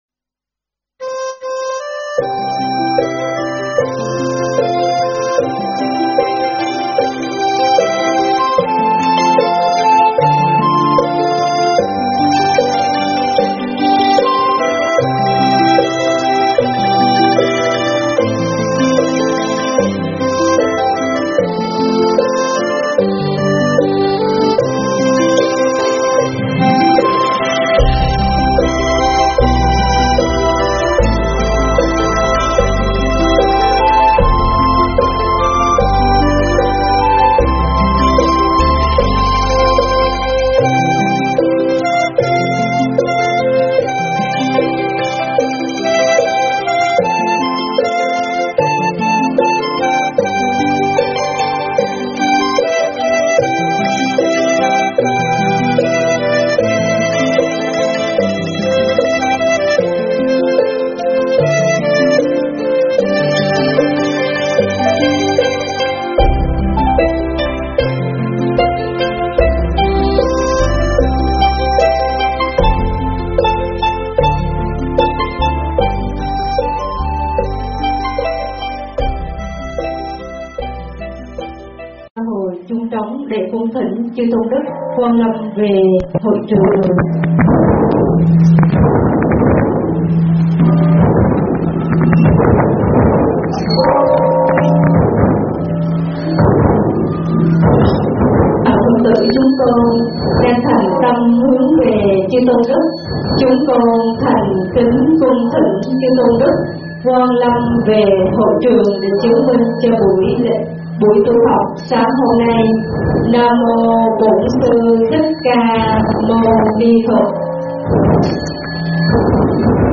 Mp3 thuyết pháp
tại Chùa Việt Nam, Kanagawa, Nhật Bản